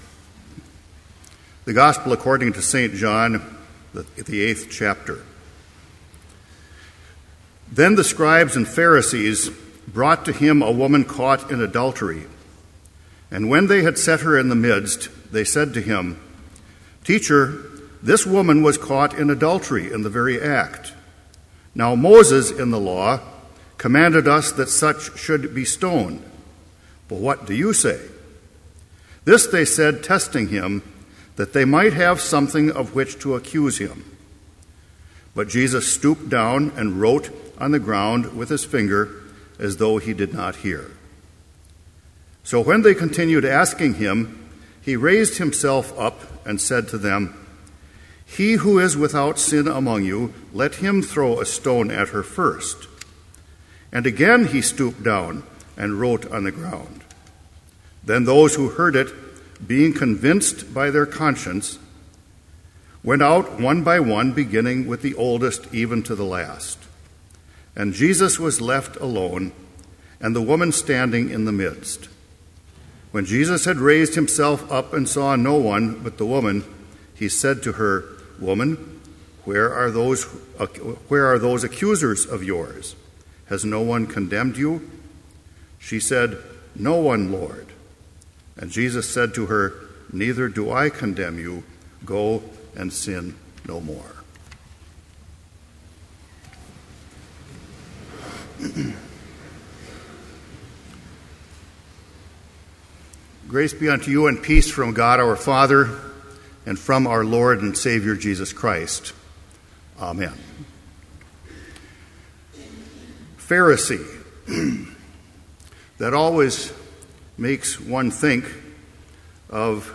Complete Service
soloist: vv. 1 & 3,
congregation: vv. 2 & 4
• Homily
This Chapel Service was held in Trinity Chapel at Bethany Lutheran College on Tuesday, October 9, 2012, at 10 a.m. Page and hymn numbers are from the Evangelical Lutheran Hymnary.